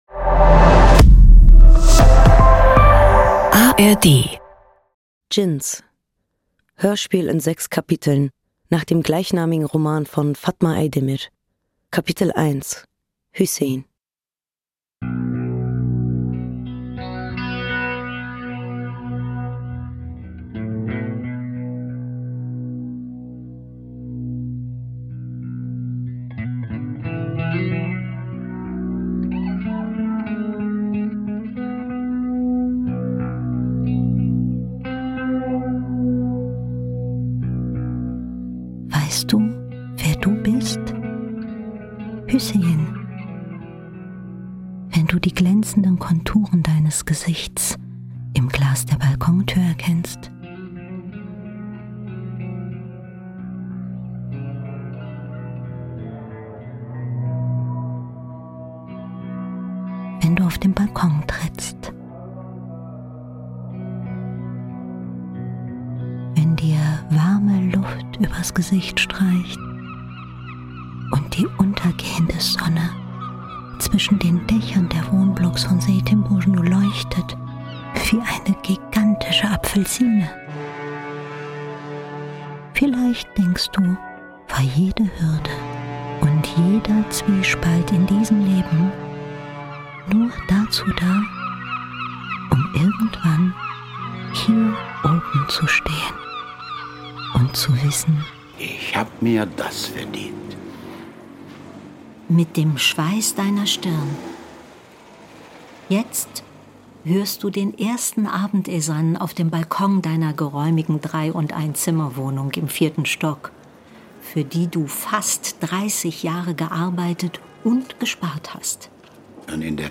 Eine Stimme aus dem Off, sein Dschinn, umkreist ihn, spricht zu ihm und gemeinsam rekapitulieren sie sein Leben.